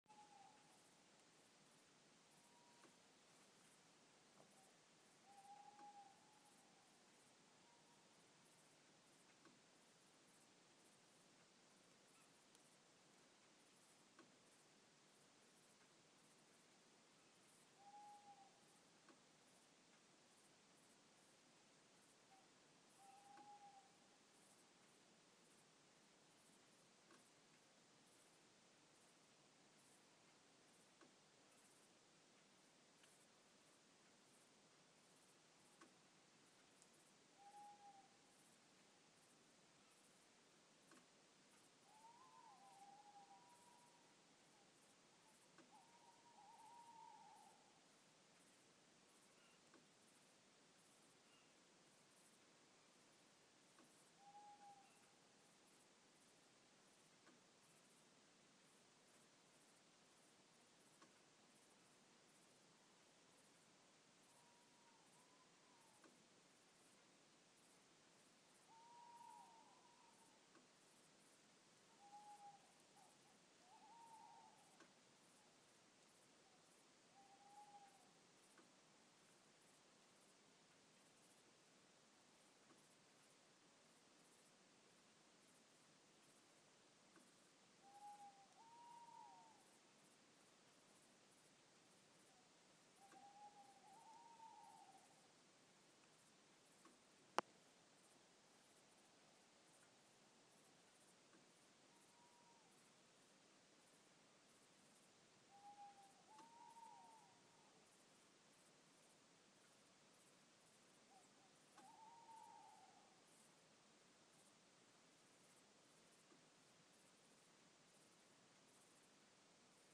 Busy night for owls
Owls. Lots of owls. And the creaking noises of a car, relaxing after a long drive.